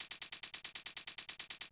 Techno / Drum / HIHAT022_TEKNO_140_X_SC2.wav